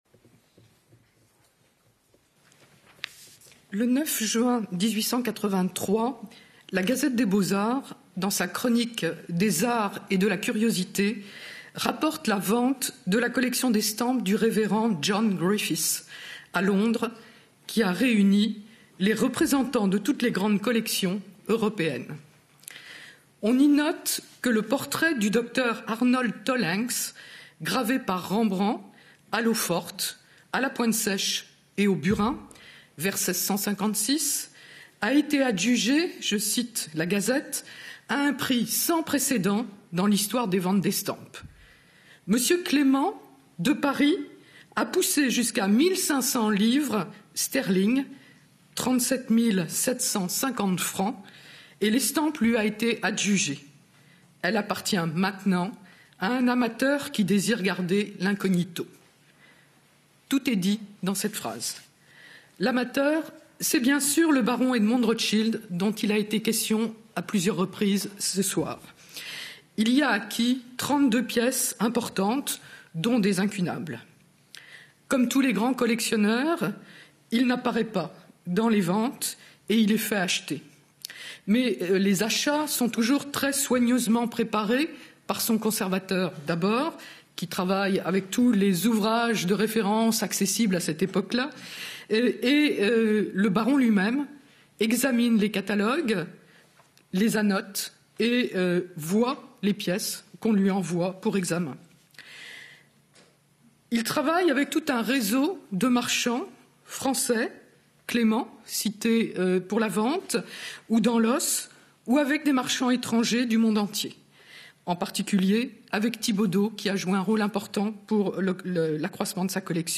Conférence